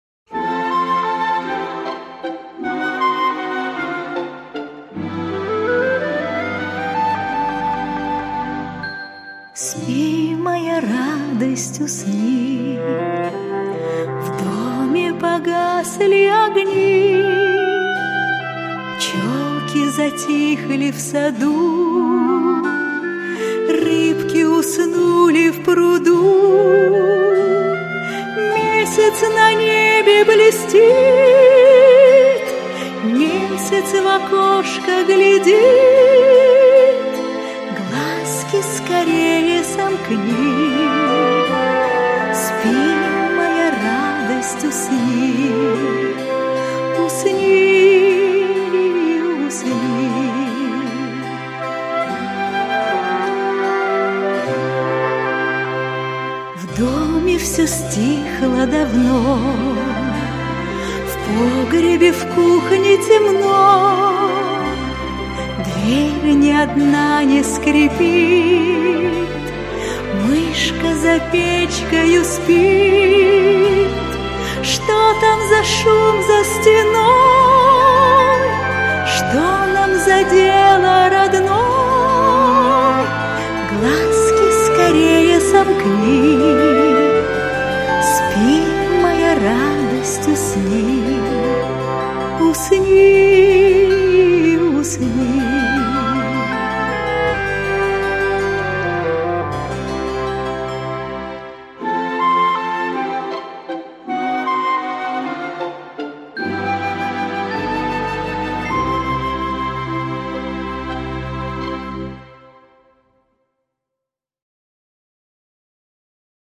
Колыбельные
песенки для малышей 0+